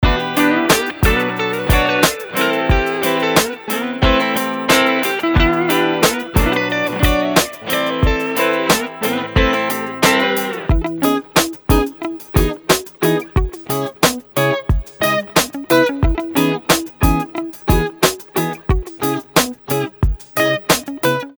A collection of smooth and crisp guitar loops, including rhythm, accent and lead riffs.